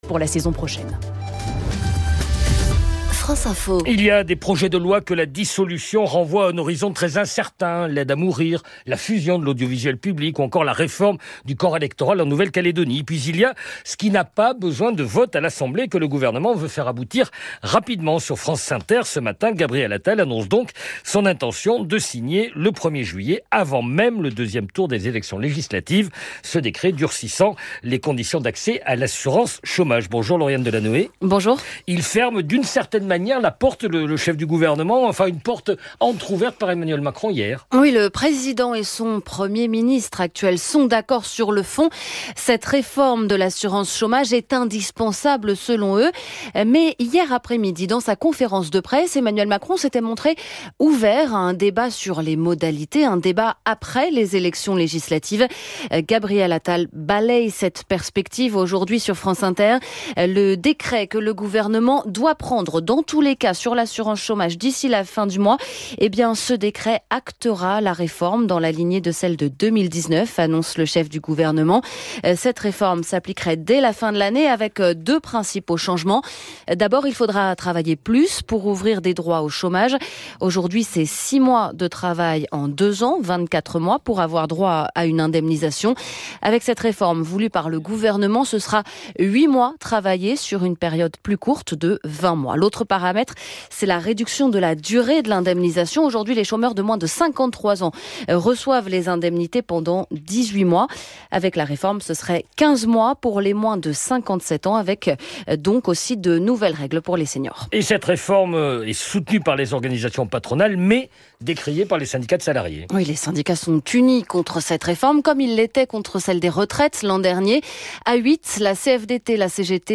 Retrouvez ci-dessous des extraits de son intervention: